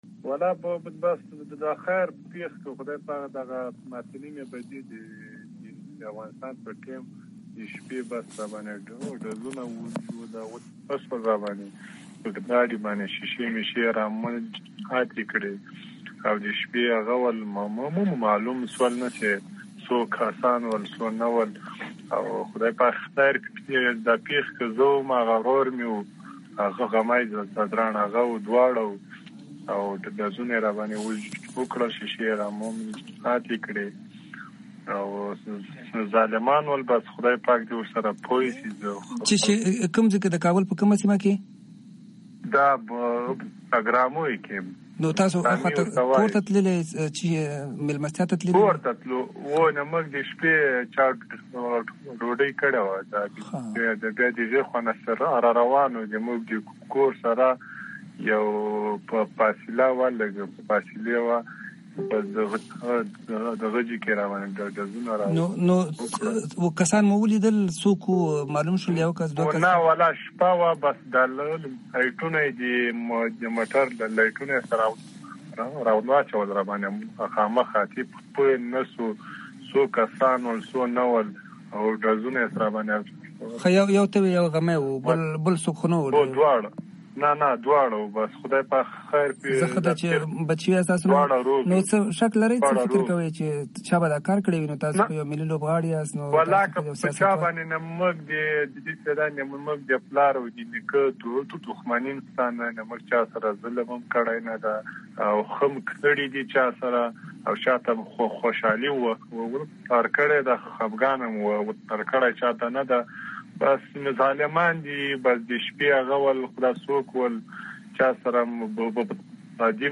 د شاپور ځدراڼ مرکه